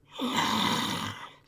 zombie_growl_023.wav